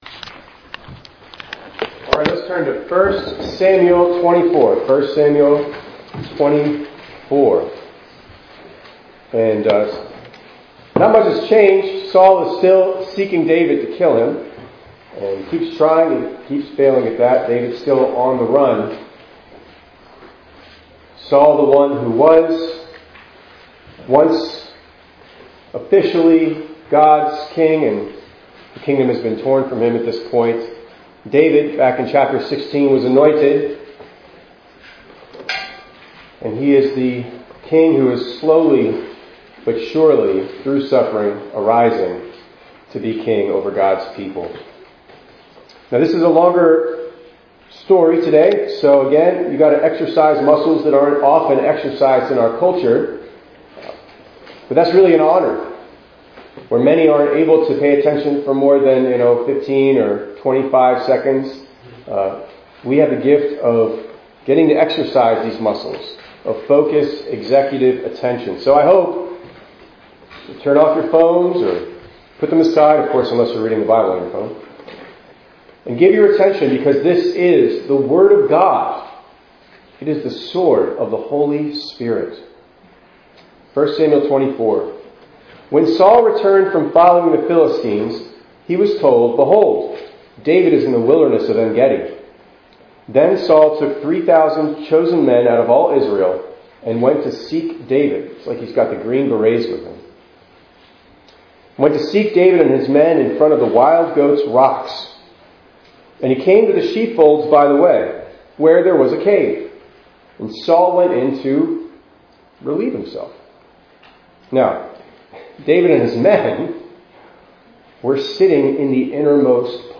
11_2_25_ENG_Sermon.mp3